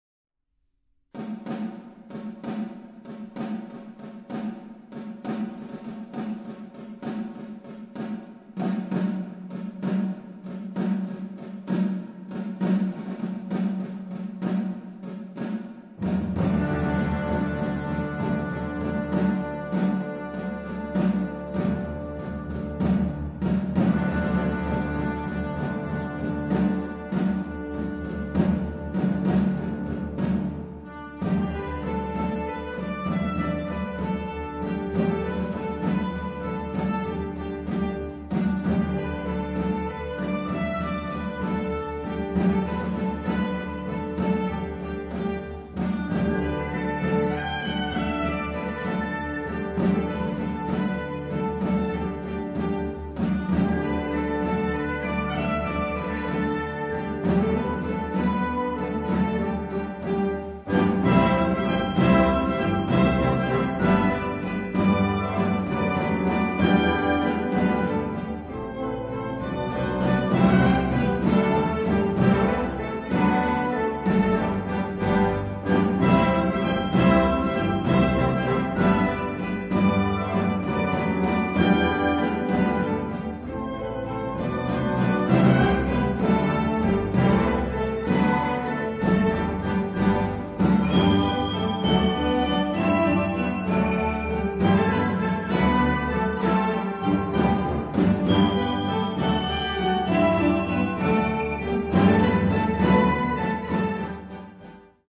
Partitions pour orchestre d’harmonie.